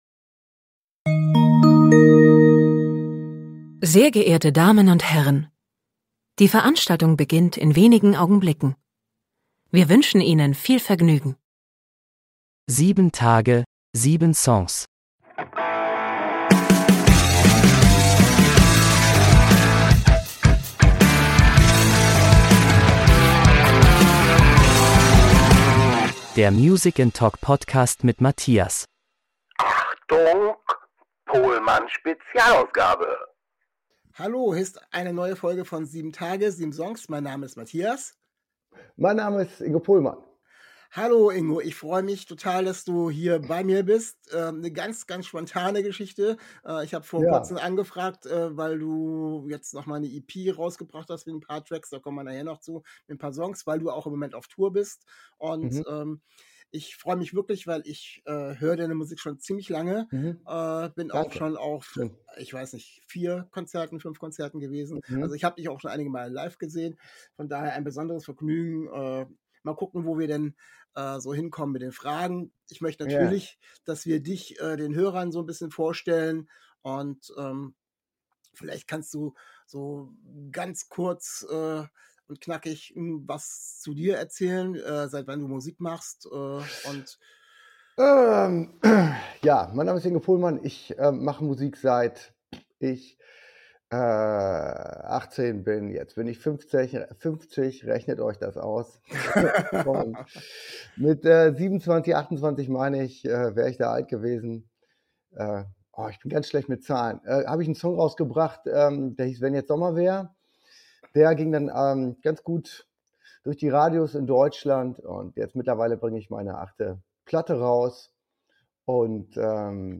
Januar 2023 Nächste Episode download Beschreibung Kapitel Teilen Abonnieren In dieser Folge ist Pohlmann zu Besuch. Wir sprechen über seine alten Hits, Live Konzert, seine neue EP und Ingo singt sogar Live einen Song.